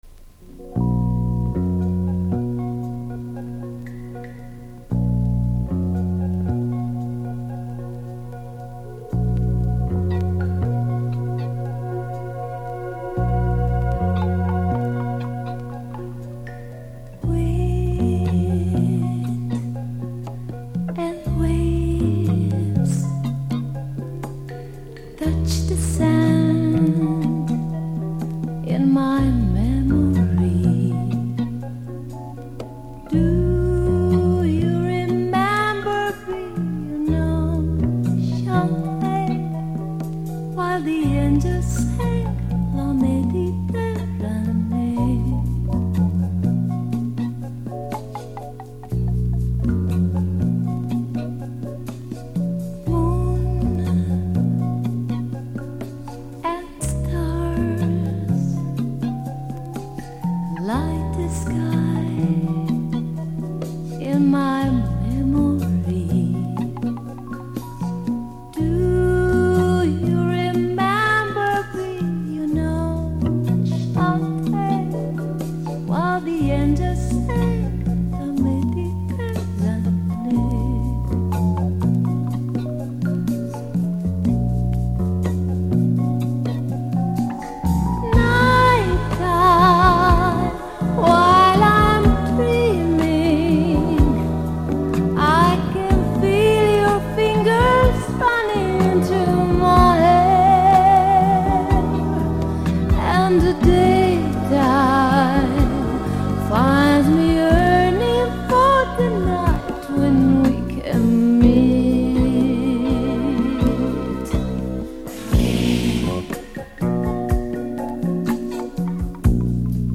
05 - SOUL